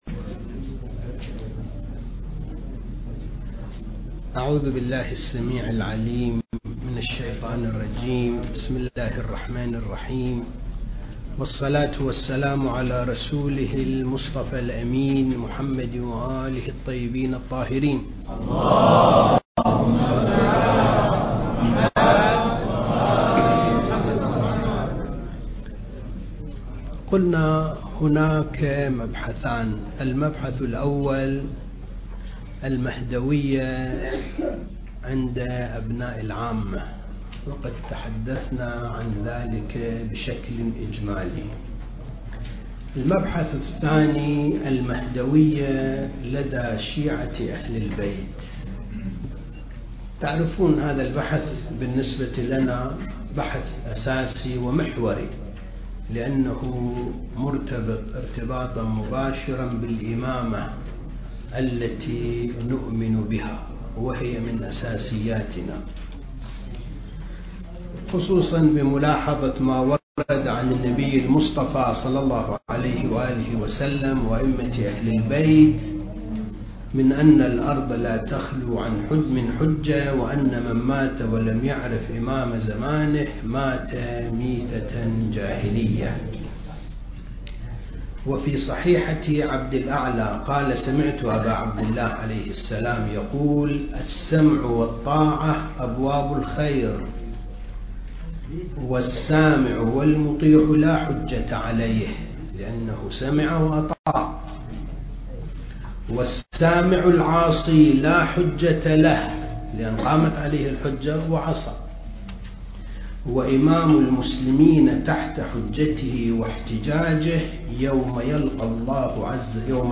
دورة الثقافة المهدوية (3) المكان: معهد وارث الأنبياء (عليهم السلام) لإعداد المبلغين العتبة الحسينية المقدسة